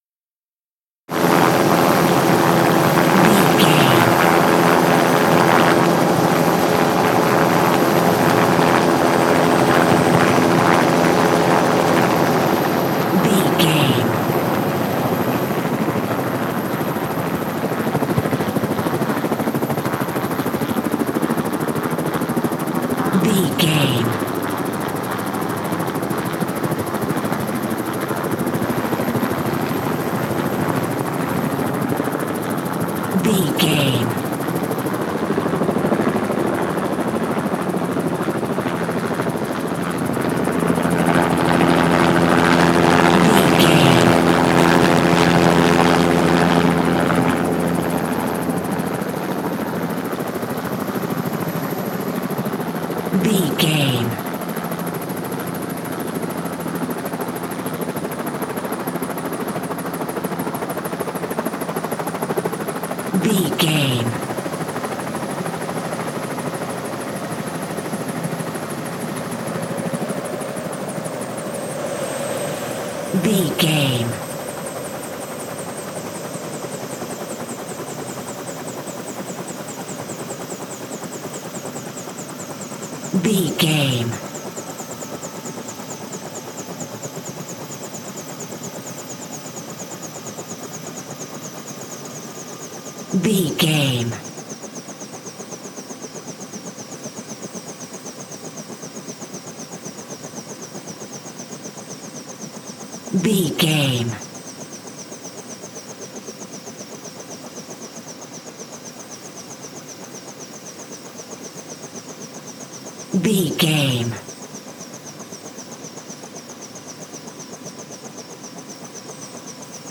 Helicopter land ext close
Sound Effects